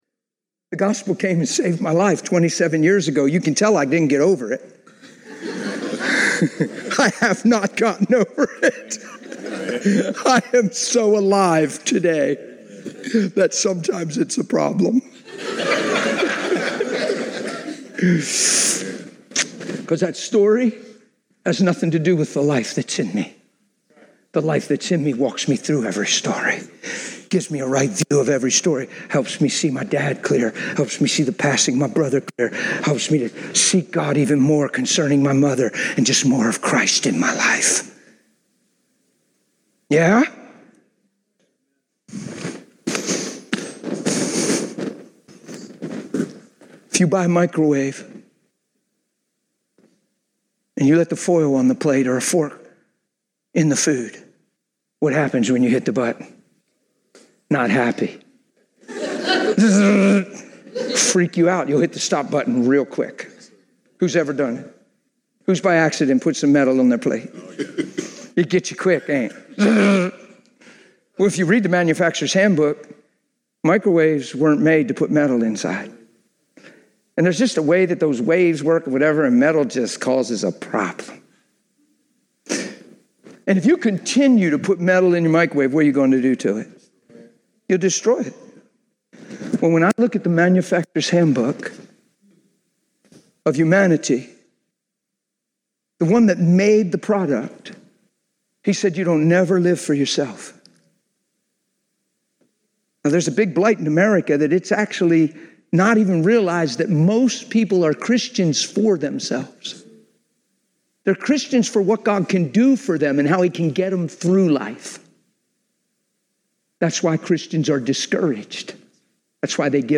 Service Type: Conference